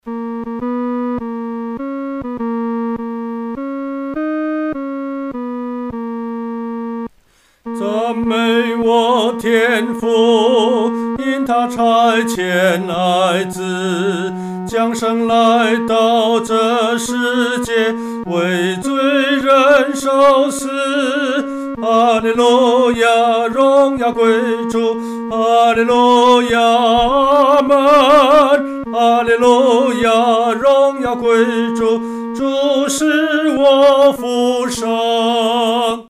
独唱（第三声）